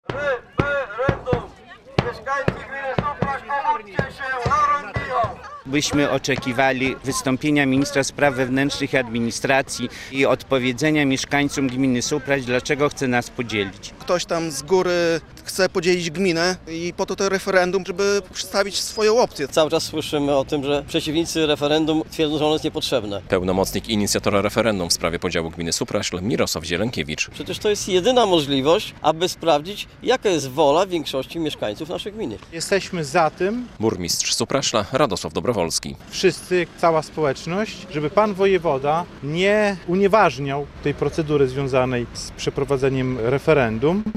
Protest przeciwników podziału gminy Supraśl - relacja